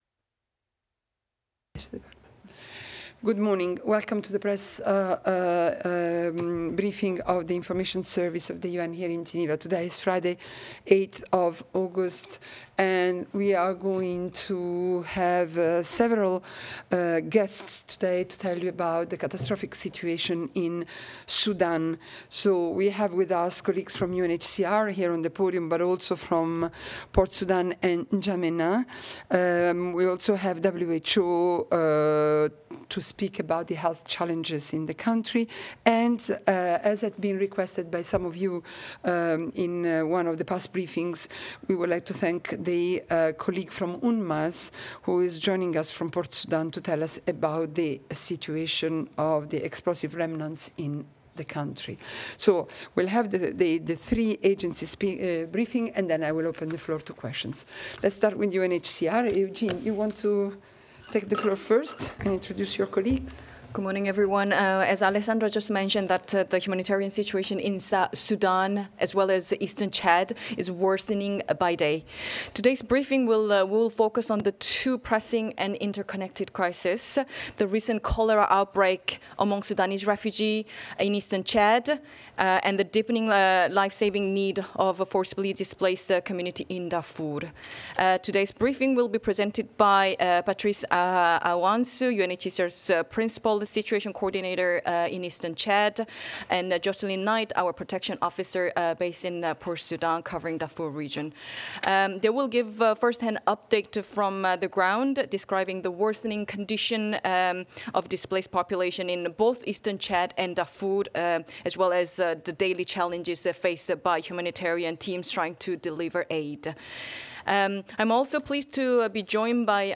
UN GENEVA PRESS BRIEFING | The United Nations Office at Geneva
Alessandra Vellucci, Director of the United Nations Information Service in Geneva, chaired a hybrid press briefing, which was attended by the representatives and spokespersons of the United Nations Refugee Agency, the World Health Organization, the United Nations Mine Action Service, and UN Habitat.